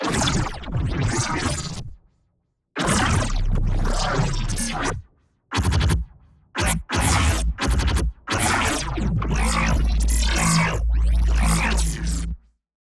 Laser Gun 5
Laser-Gun-05-Example.mp3